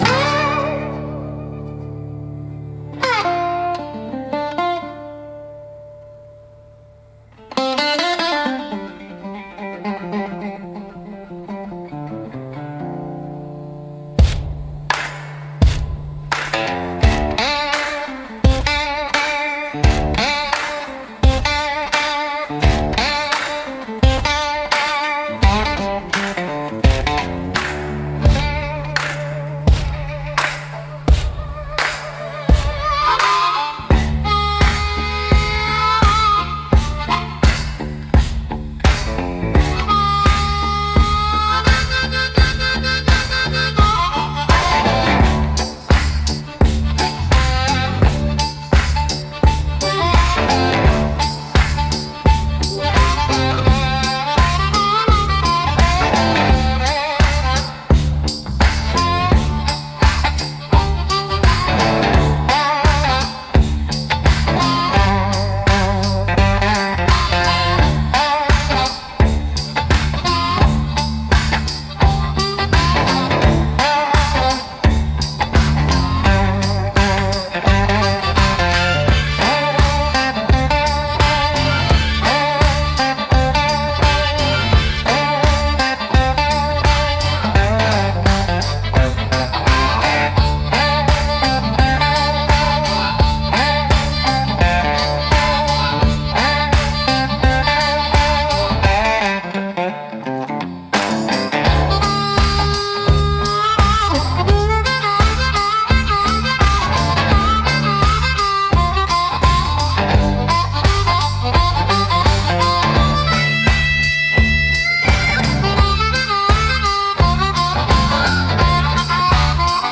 Instrumental - Deacon's Revolt 3.17